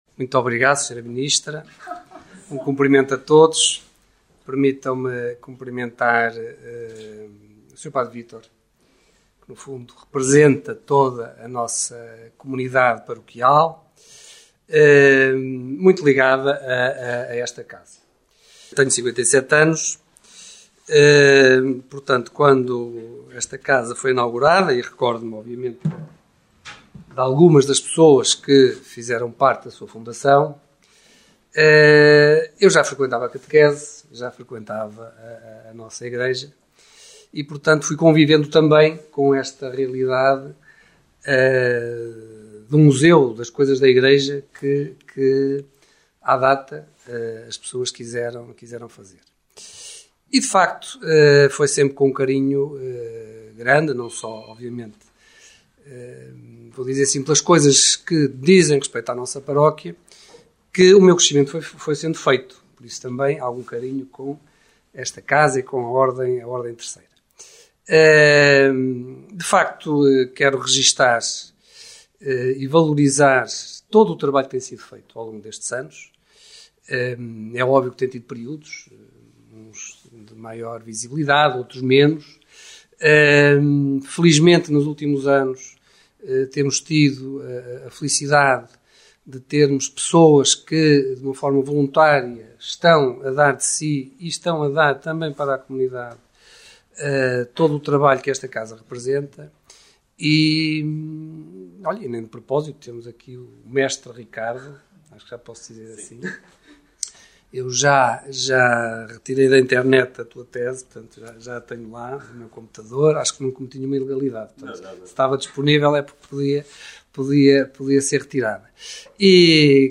Domingos Silva, vice-presidente da Câmara Municipal de Ovar (CMO), começou por recordar a altura em que frequentava a catequese e assistira à criação do museu, constatando que foi nutrindo um carinho pela missão do espaço que se dedicava à salvaguarda de artigos da igreja. O autarca parabenizou a CMAS pelo seu aniversário e por todo o trabalho que tem realizado na valorização do património religioso.